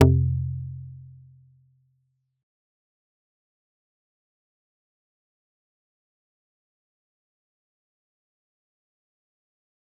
G_Kalimba-F2-mf.wav